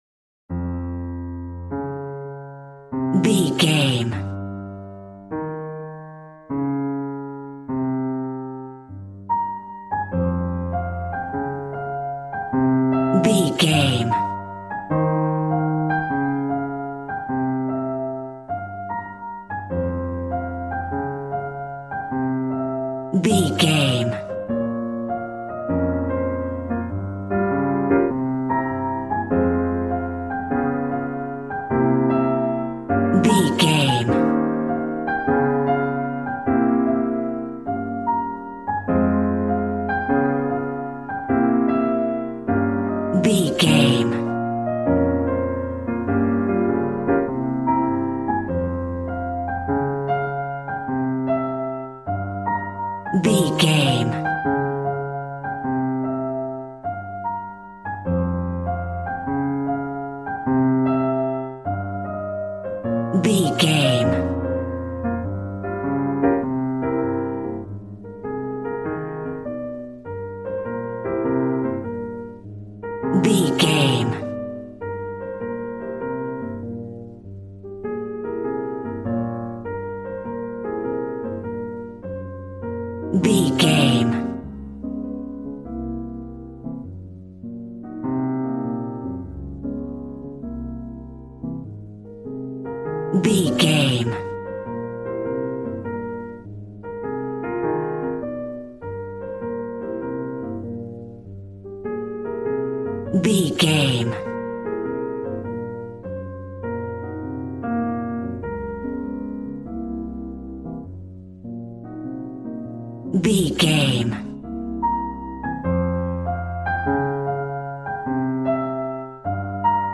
Smooth jazz piano mixed with jazz bass and cool jazz drums.,
Aeolian/Minor
D
piano
drums